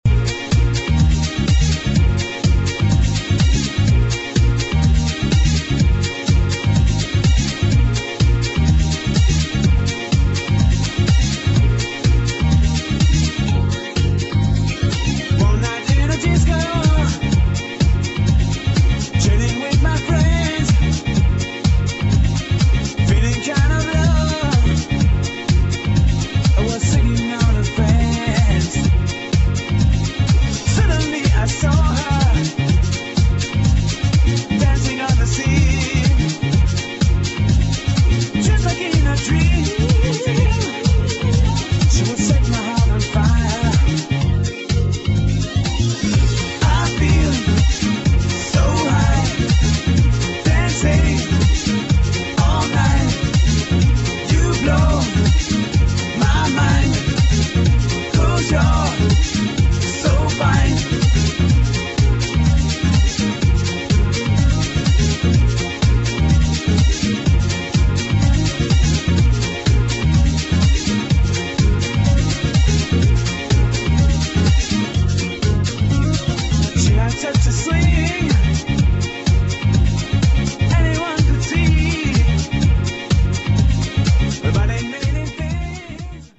[ FRENCH HOUSE ]